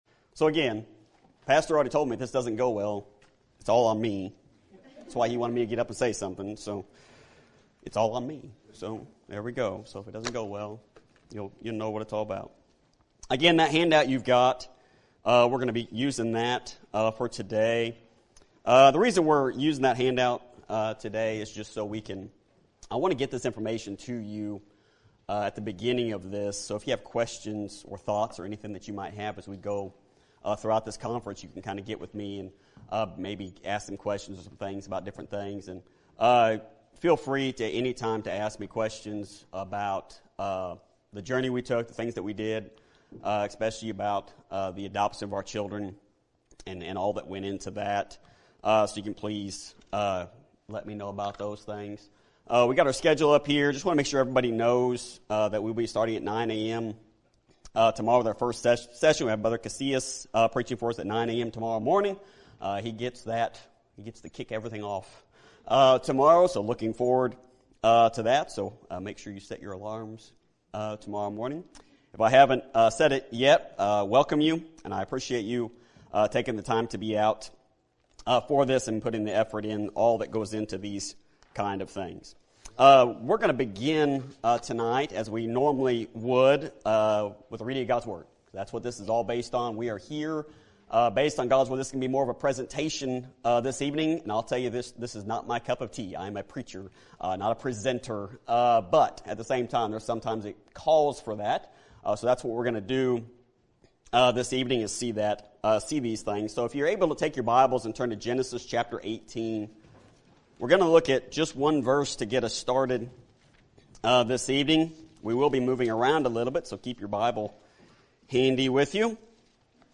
Sermon Topic: Lonely Road Conference Sermon Type: Special Sermon Audio: Sermon download: Download (19.76 MB) Sermon Tags: Lonely Infertility Adoption Couples